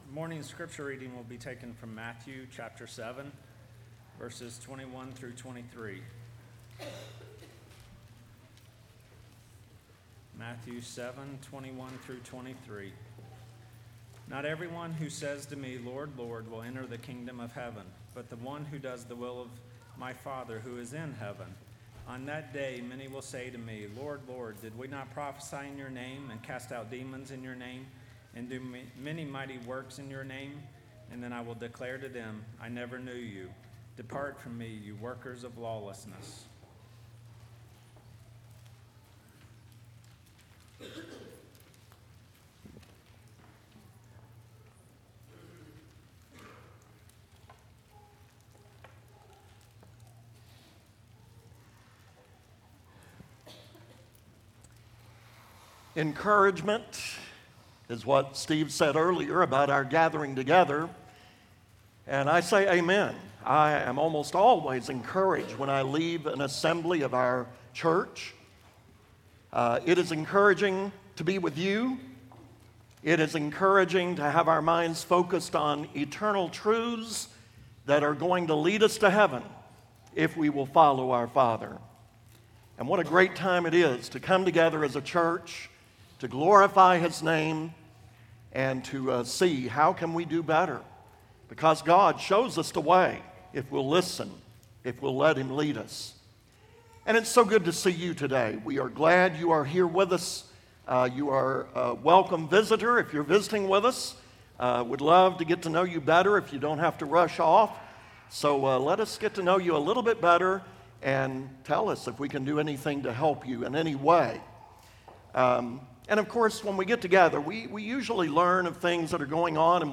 Matthew 22:14 (English Standard Version) Series: Sunday AM Service